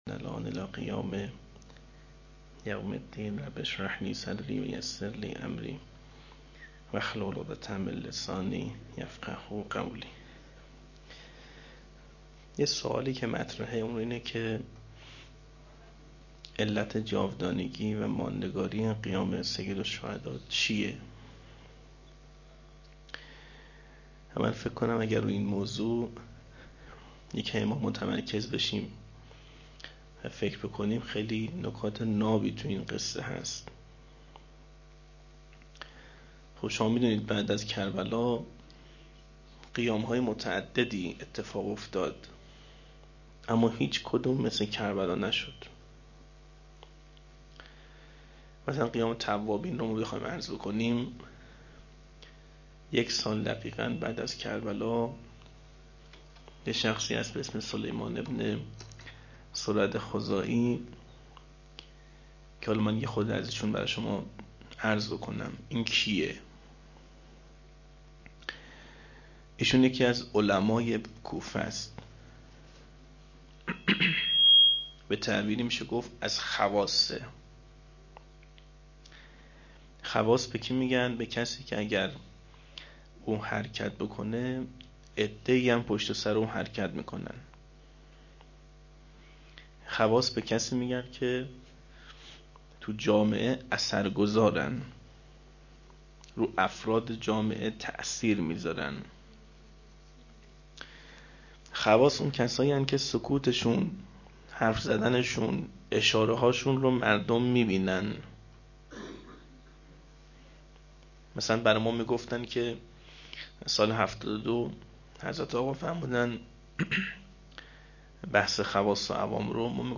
01-shab2-sokhanrani.mp3